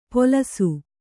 ♪ polasu